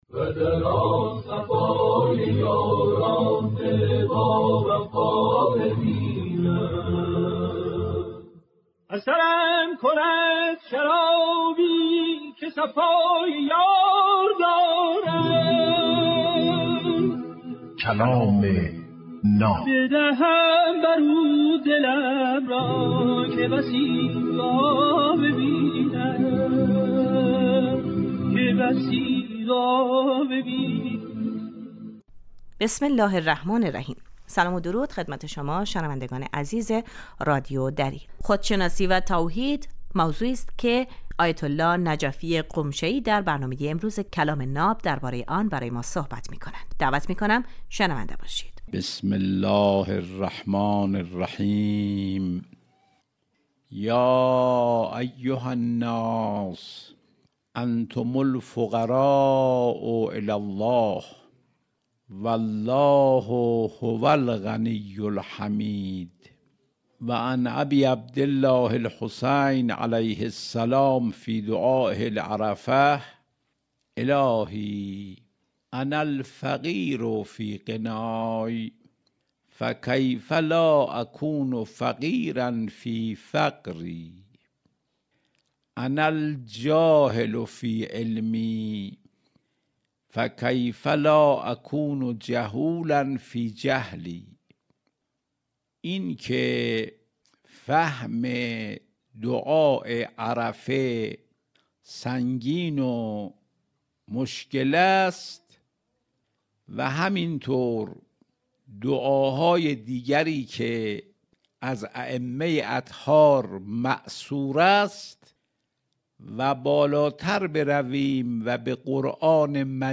در این برنامه هر روز یک سخنرانی آموزنده کوتاه پخش می شود.